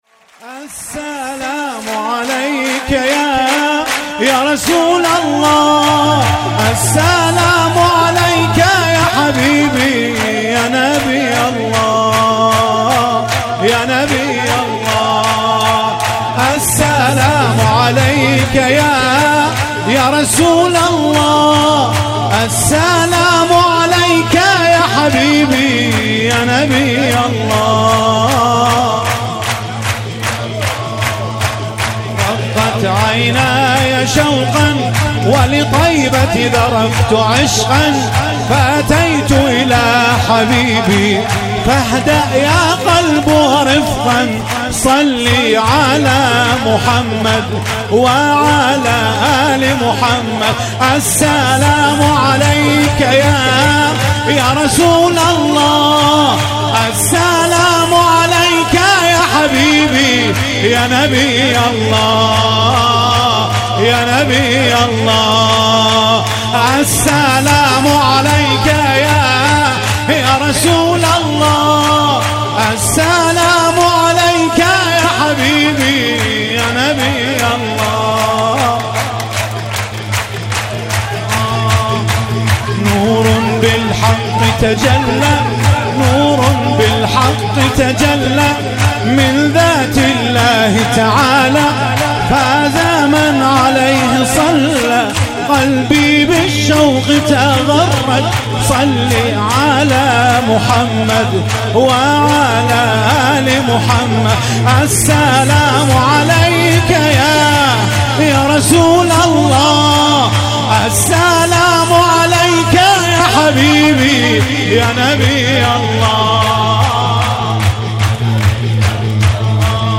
مولودی شاد پیامبر